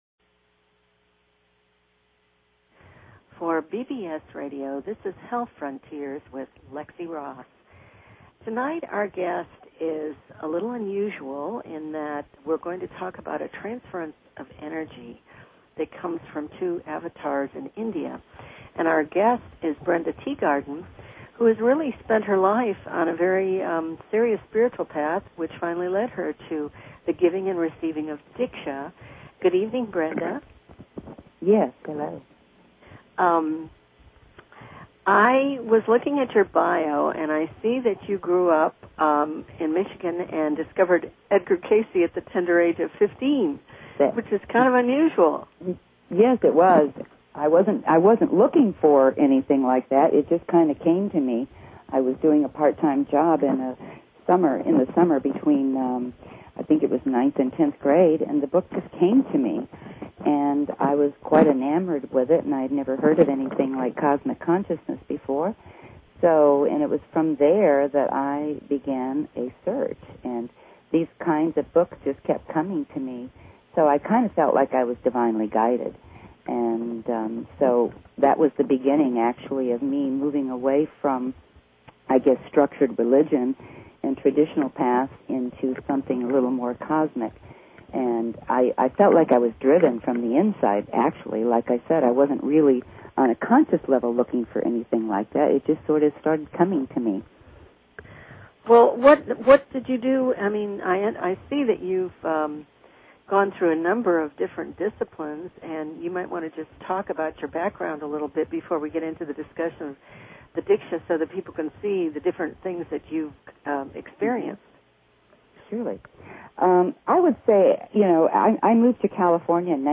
Courtesy of BBS Radio